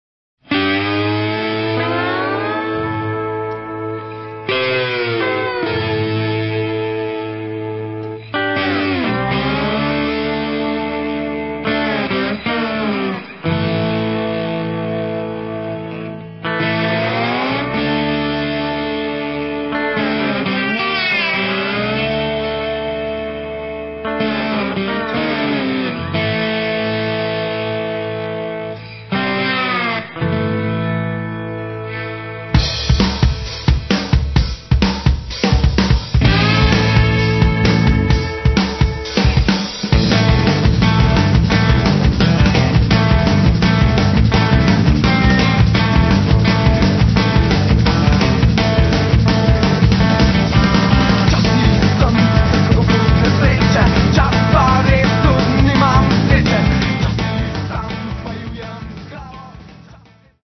Enominutni Lo-Fi MP3 izsečki za hitro predstavitev ...